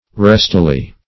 restily - definition of restily - synonyms, pronunciation, spelling from Free Dictionary Search Result for " restily" : The Collaborative International Dictionary of English v.0.48: Restily \Rest"i*ly\ (r?st"?*l?), adv.